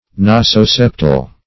Search Result for " nasoseptal" : The Collaborative International Dictionary of English v.0.48: Nasoseptal \Na`so*sep"tal\, a. [Naso- + septal.]